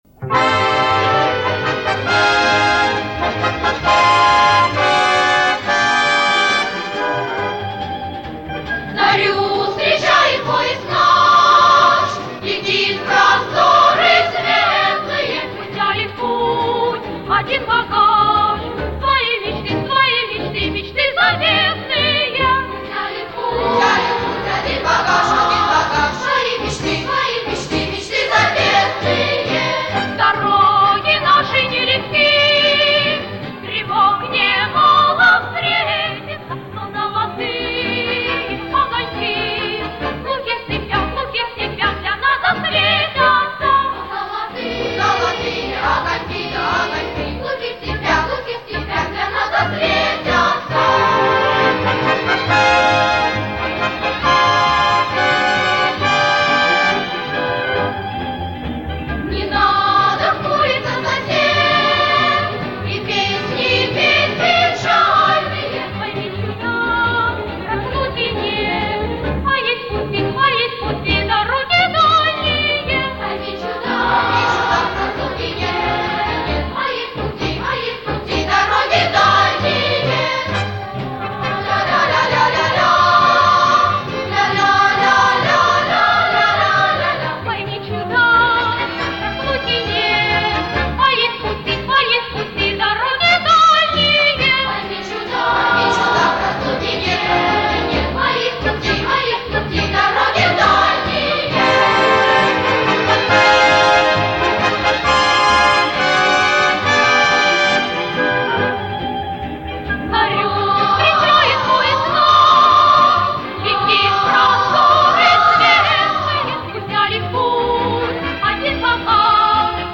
Концертное исполнение.